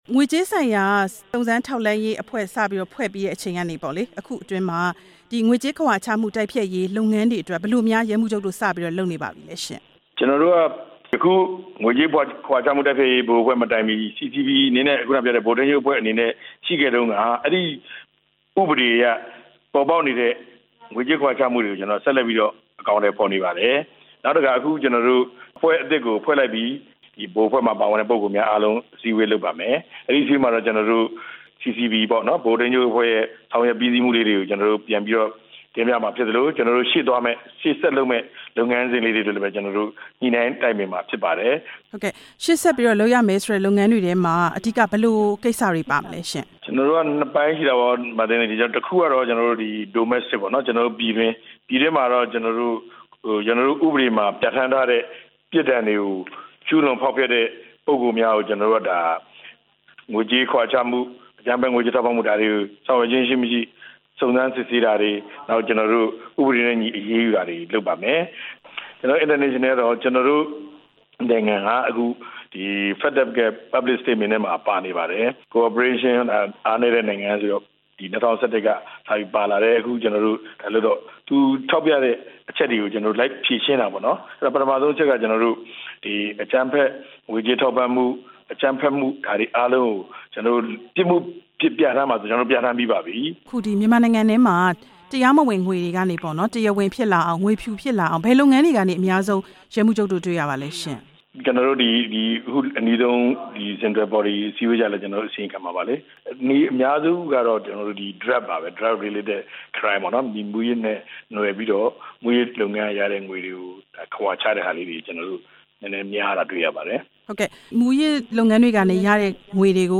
ငွေကြေး စုံစမ်းထောက်လှမ်းရေးအဖွဲ့နဲ့ ဆက်သွယ်မေးမြန်းချက်